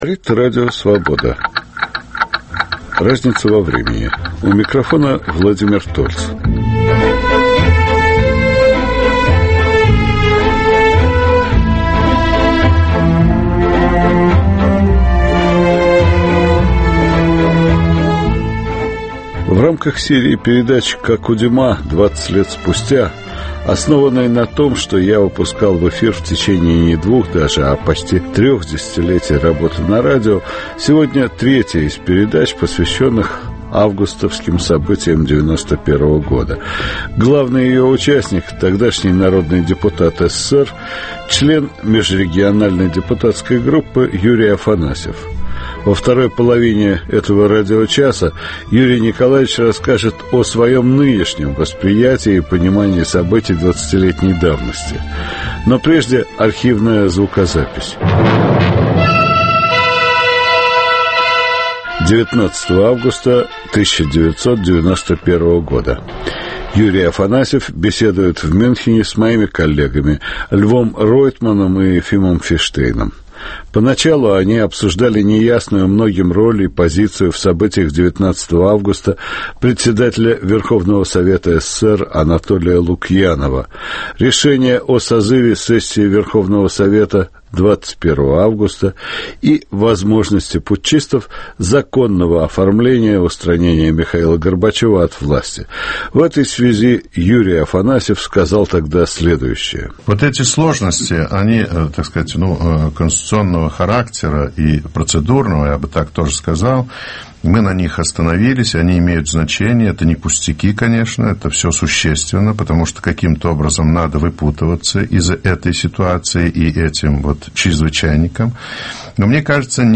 (Записи 1991 и 2011 гг.).